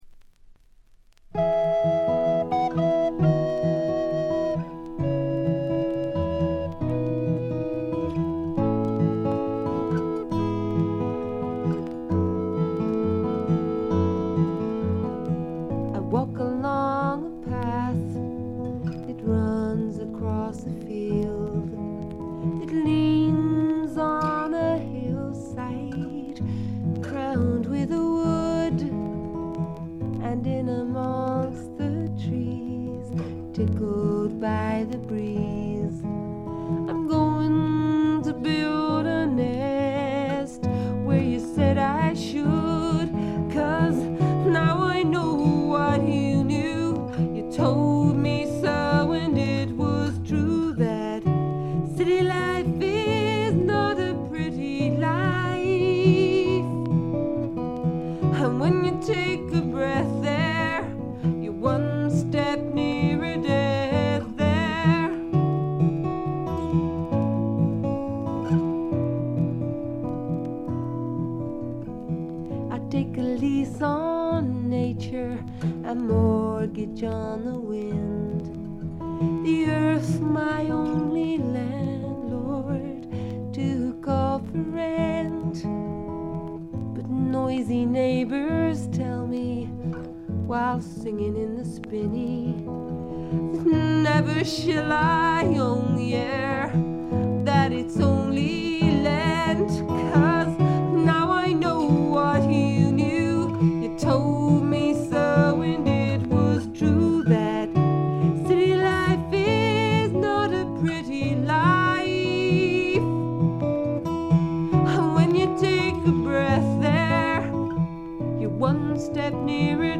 バックグラウンドノイズが常時出ており静音部ではやや目立ちます。
英国の女性シンガー・ソングライター／フォークシンガー。
内容は80sぽさはまったくなく70年代のシンガー・ソングライター黄金期の空気感が全体をおおっていてとても良い感じ。
試聴曲は現品からの取り込み音源です。